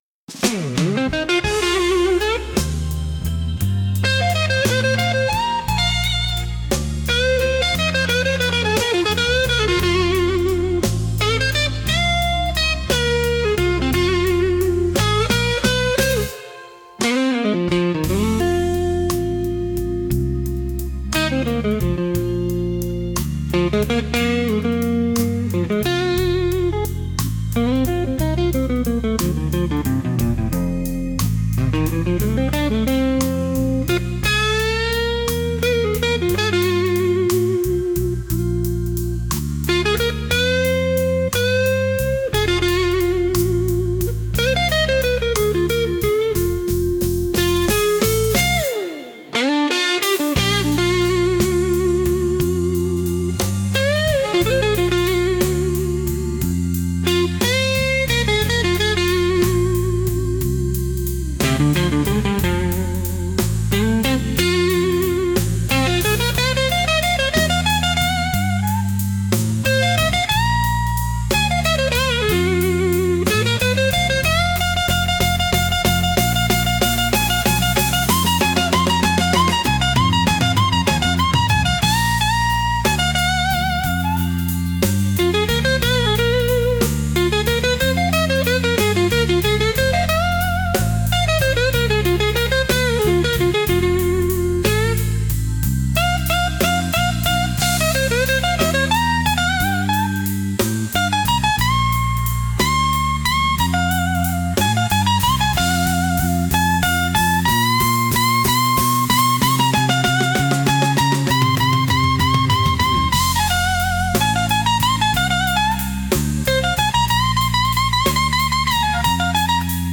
Instrumental - Join Real Liberty Media's PeerTube 5. 48 .mp3